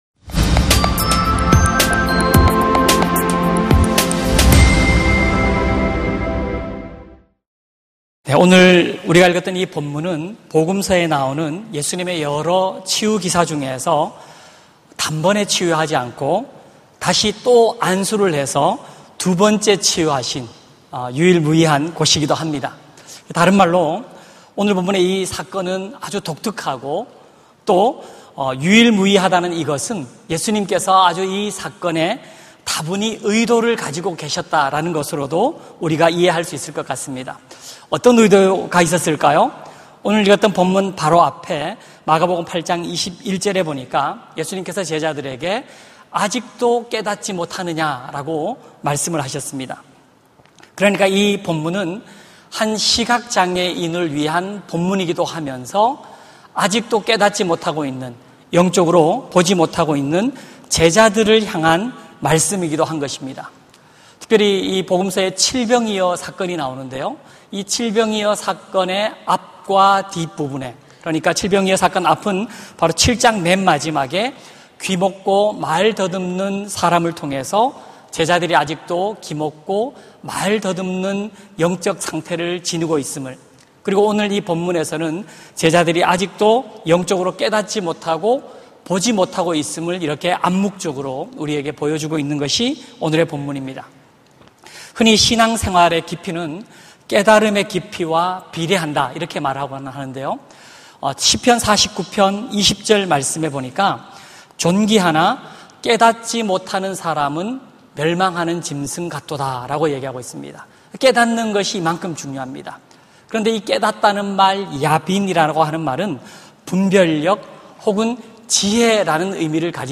설교 : 금요심야기도회